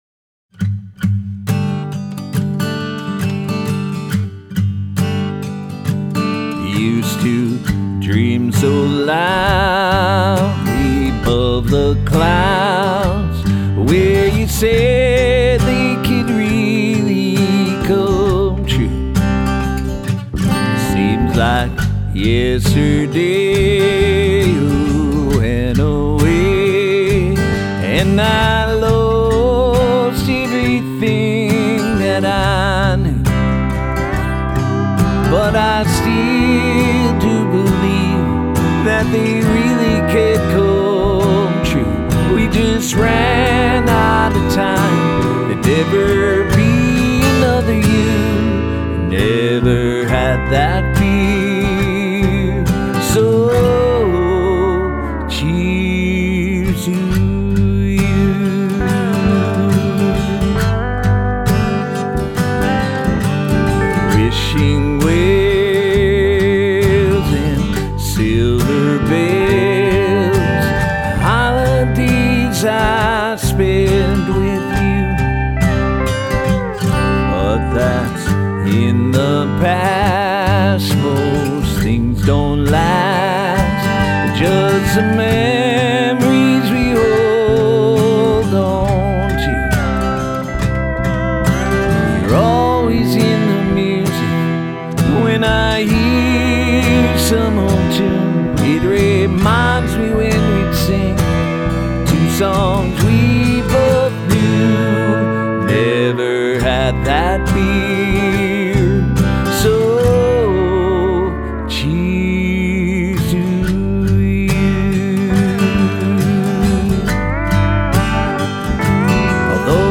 Minnesota Folk Singer and Songwriter